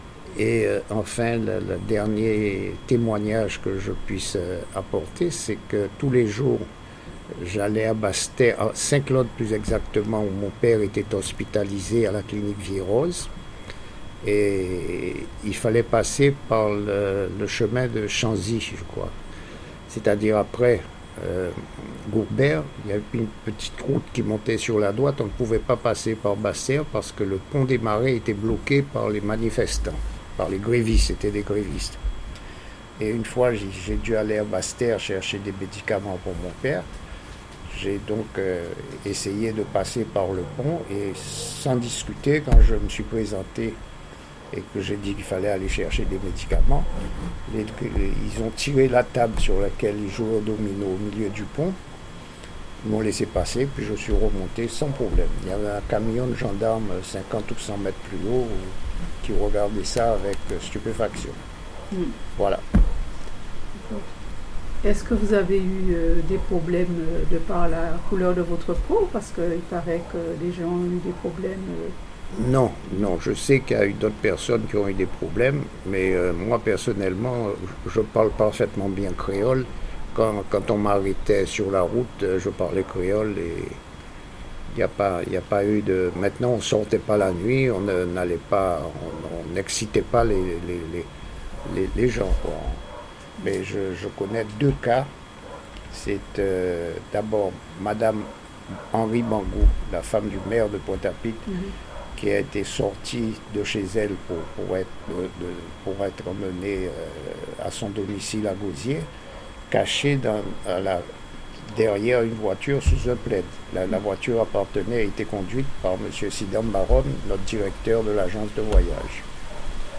Témoignage sur les évènements de mai 1967 à Pointe-à-Pitre.
Intégralité de l'interview.